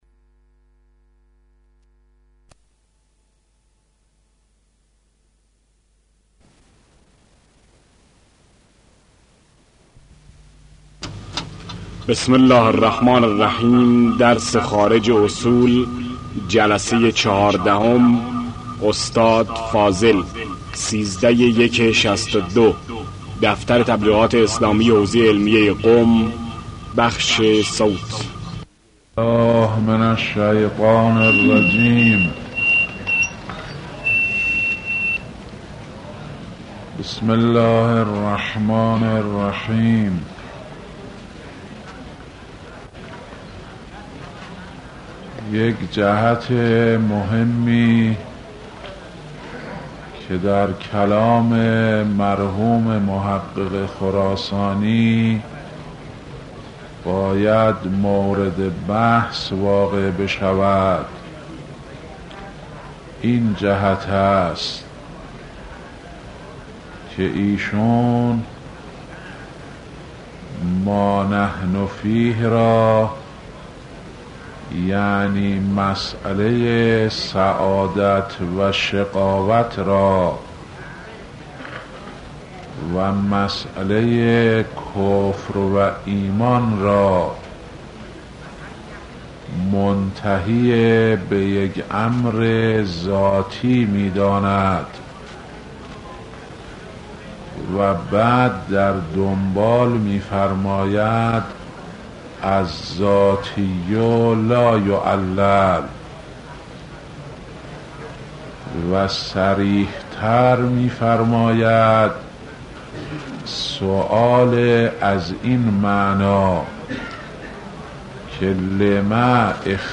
آيت الله فاضل لنکراني - خارج اصول | مرجع دانلود دروس صوتی حوزه علمیه دفتر تبلیغات اسلامی قم- بیان